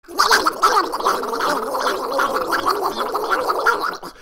Дональда Дака трясут и он издает странные звуки